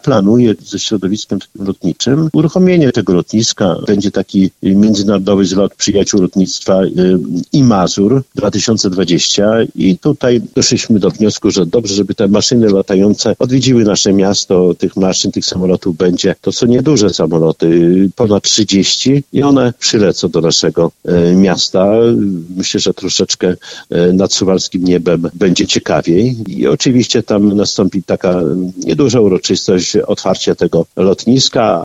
– Na suwalskim niebie zrobi się ciekawie – mówi Czesław Renkiewicz, prezydent miasta.